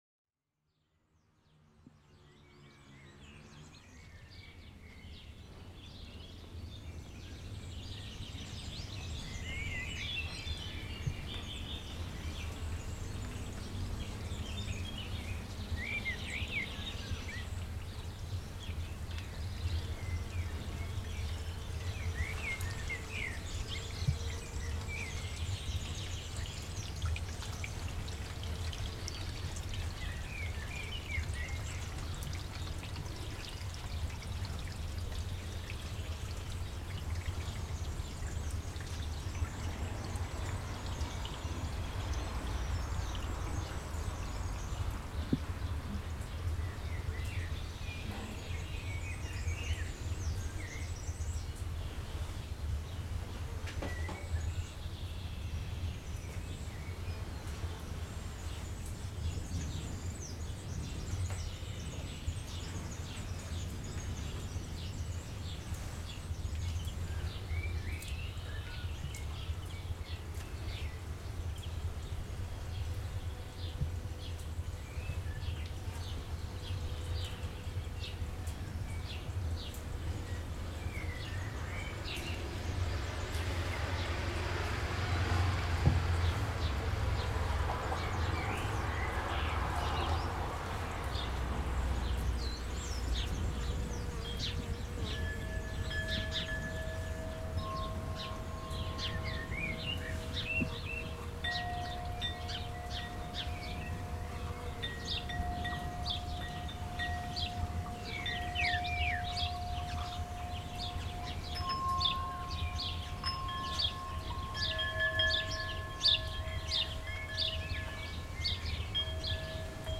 for soundwalk and church organ
Composition for sound walk &church organ